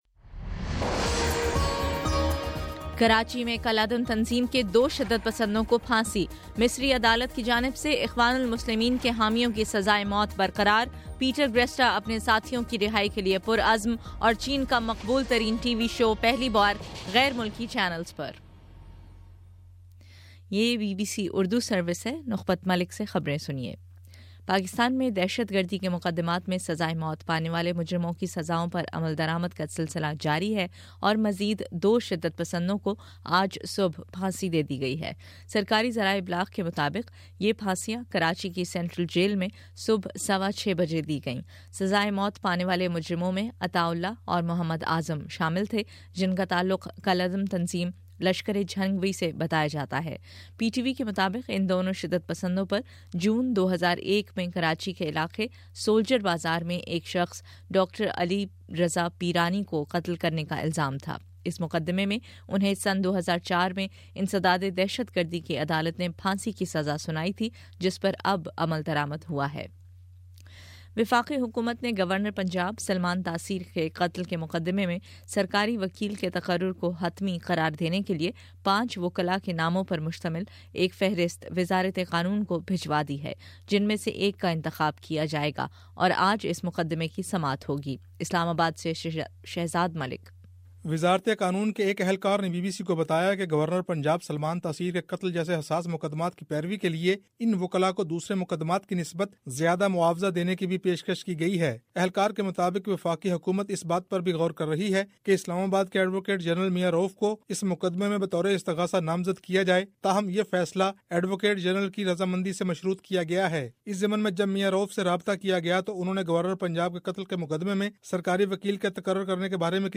فروری 03: صبح نو بجے کا نیوز بُلیٹن